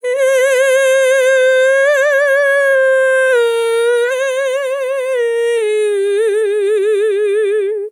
TEN VOCAL FILL 19 Sample
Categories: Vocals Tags: dry, english, female, fill, sample, TEN VOCAL FILL, Tension
POLI-VOCAL-Fills-100bpm-A-19.wav